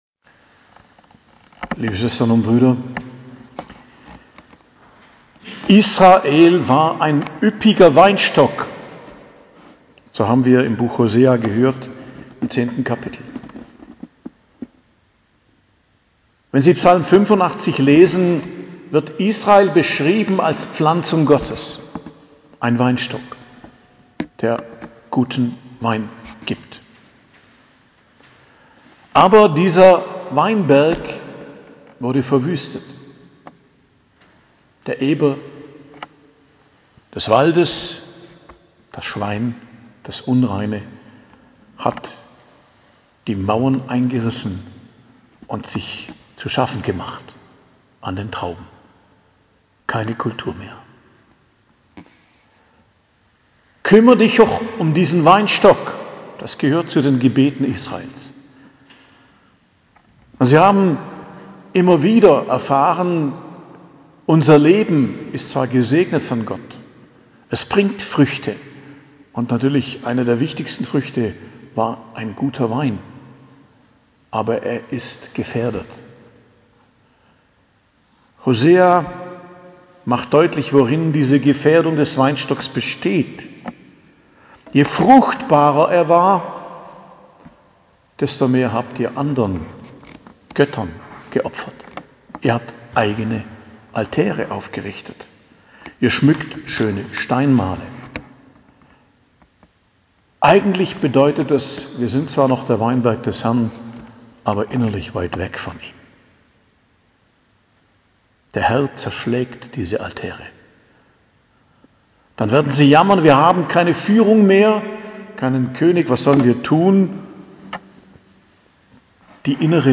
Predigt am Mittwoch der 14. Woche i.J., 6.07.2022